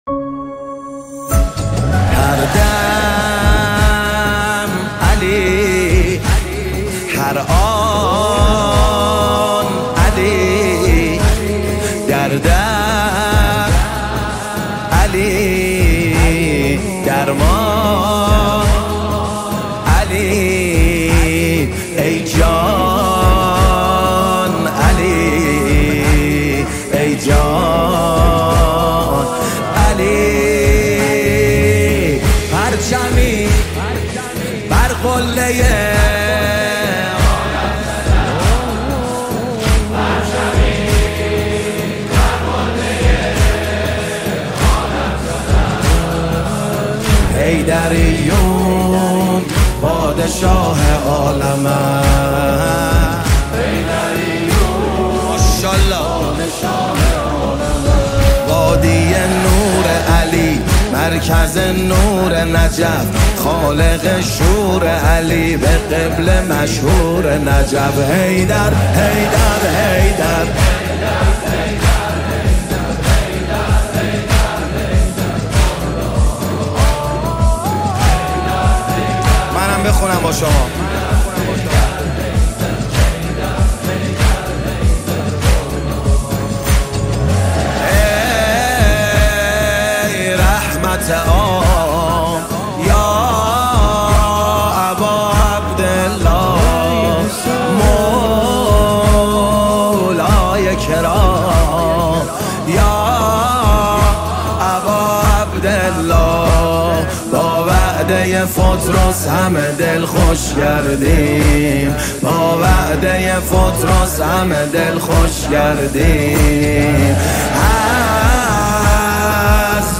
دانلود نماهنگ دلنشین
نماهنگ دلنشین